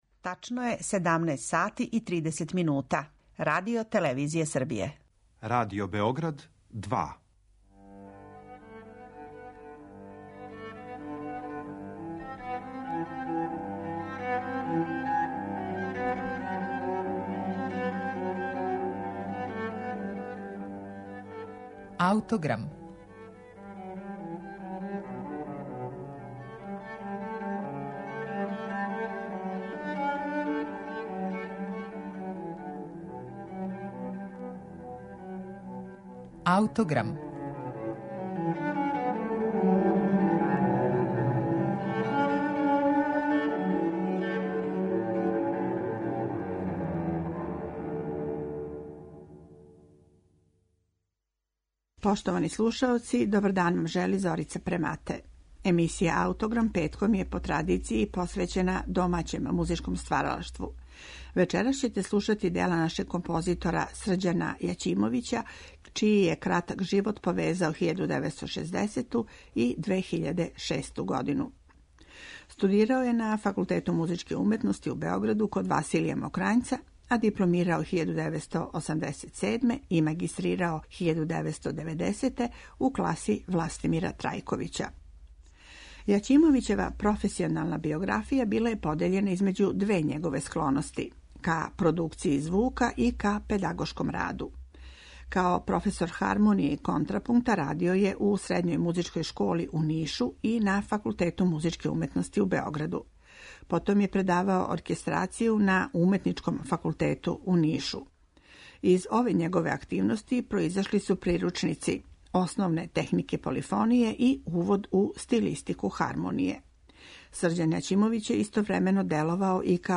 Овога пута представићемо вам оркестарско дело „Сцене са погледом уназад" нашег савременог композитора Срђана Јаћимовића, угледног продуцента Музичке продукције РТС-а, који је прерано преминуо пре тринаест година.